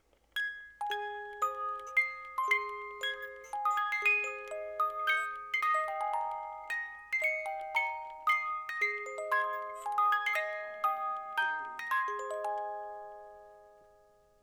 Spieluhr mit dem Volkslied: Oh My Darling Clementine
der Korpus besteht aus Bambus
die Spieluhr besitzt ein Qualitätsspielwerk mit 18 Zungen
Der Ton dieser Spieluhren ist klar, warm und obertonreich und wird durch Kurbeln eines Qualitätsspielwerks erzeugt. Dabei erklingt die Western-Music-Ballade: Oh My Darling Clementine.